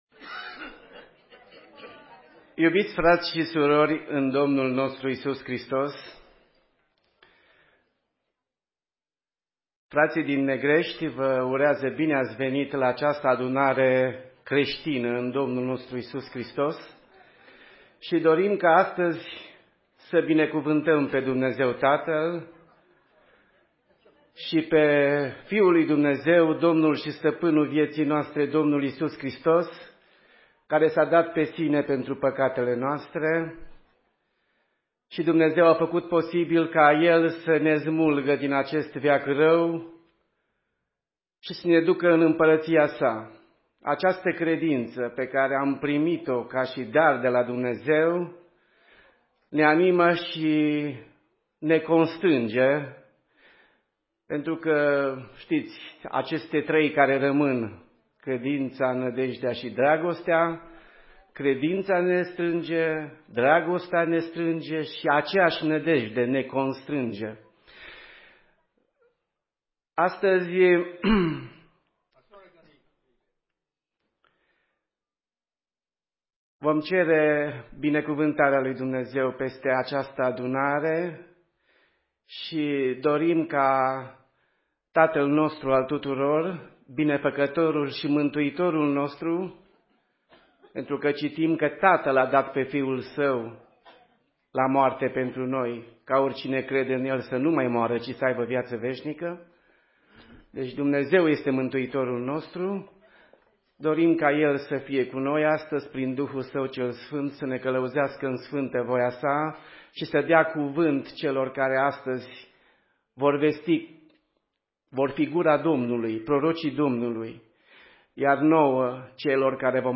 Conventia_Negresti_Oas_2017.mp3